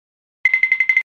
Звуки мультяшного бега
На этой странице собрана коллекция забавных звуков мультяшного бега — от быстрых шлепающих шагов до комичных \